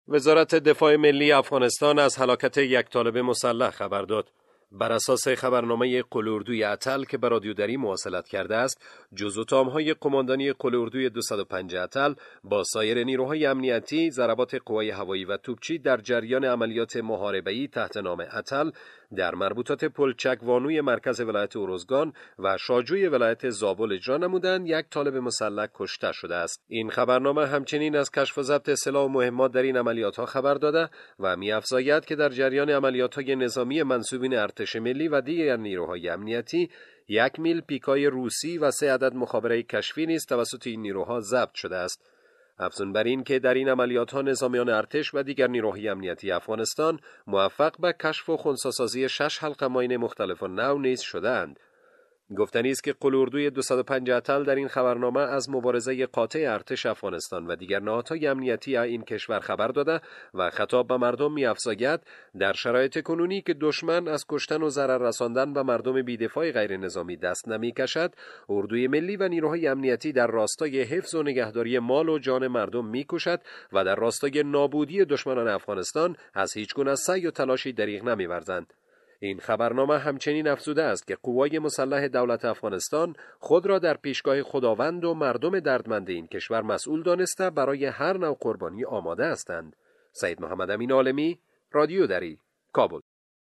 گزارش؛ هلاکت یک طالب مسلح در مربوطات پلچک وانو مرکز ولایت ارزگان